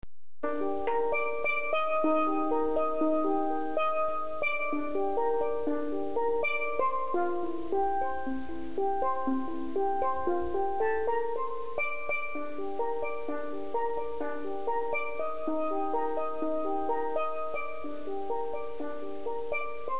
C Bore Lead
The C Bore Lead is a melody pan with 29 notes, arranged in 4ths and 5ths, the same as the C lead, but has a deeper face bored with holes separating the notes and a 9" skirt. This pan is preferred by solo pan players and steelbands who wish the Lead to have more resonance.
Range = 1st Violin = Soprano